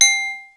Index of /kb6/Akai_XR-10/Percussion
Triangle.wav